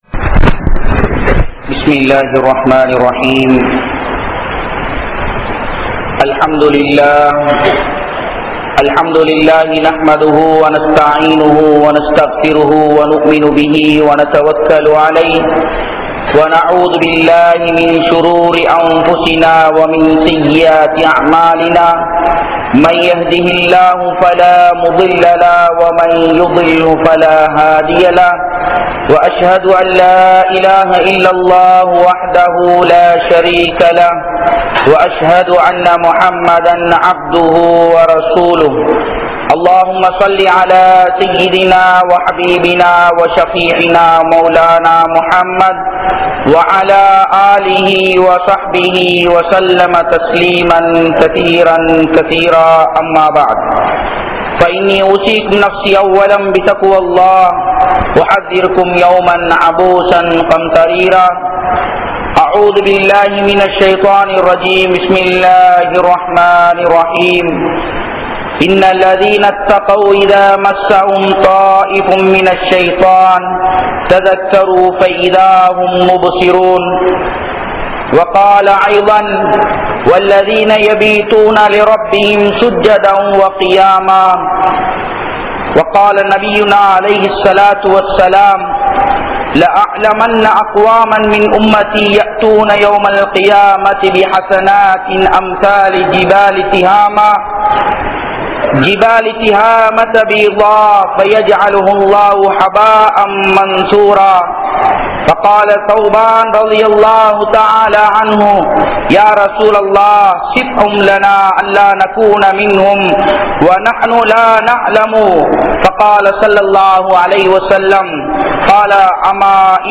Ilaigarhalai Seeralikkum Mobile Phone (இளைஞர்களை சீரழிக்கும் மொபைல் போன்) | Audio Bayans | All Ceylon Muslim Youth Community | Addalaichenai
Kandy, Digana, Akbar Jumua Masjidh